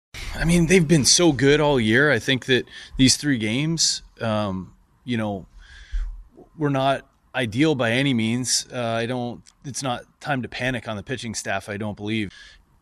Kelly says the Pirates didn’t get the pitching they expected in the White Sox series, but he remains confident in this staff.